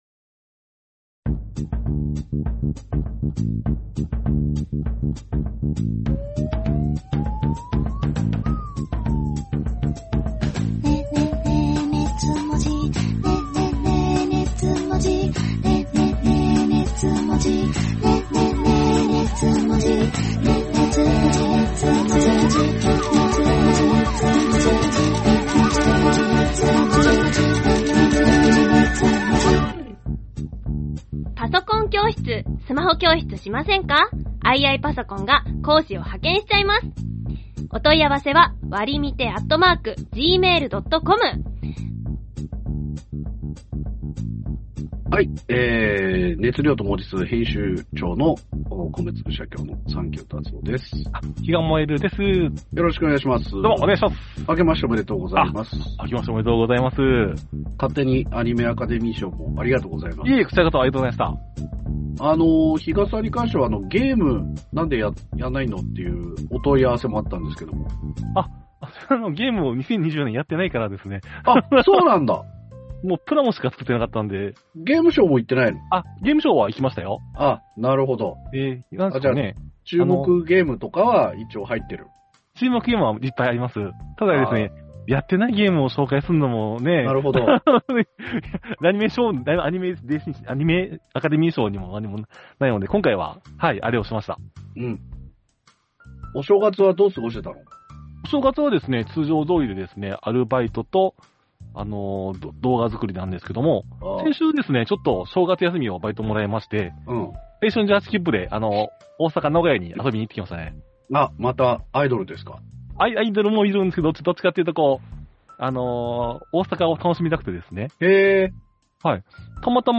オタク芸人 サンキュータツオ Presents 二次元を哲学するトークバラエティ音声マガジン『熱量と文字数』のブログです。